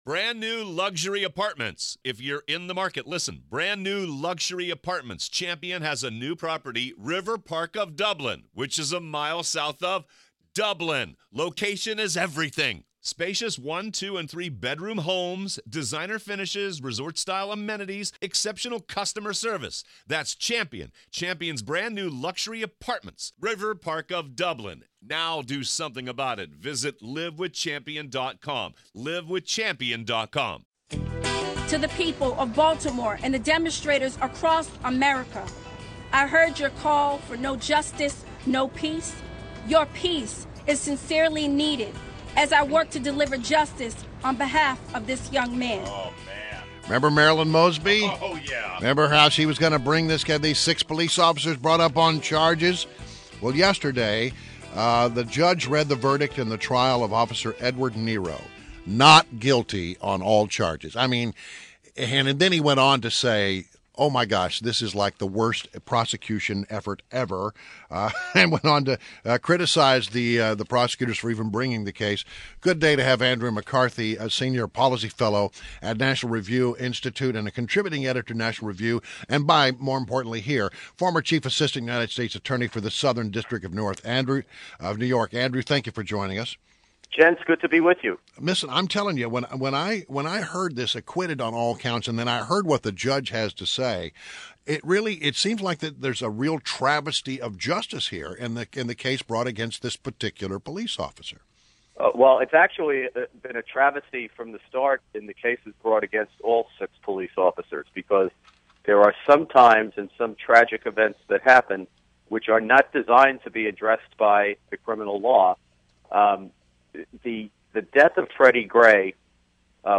WMAL Interview - ANDREW MCCARTHY 05.24.16
INTERVIEW — ANDREW MCCARTHY — is a senior policy fellow at the National Review Institute and a contributing editor of National Review.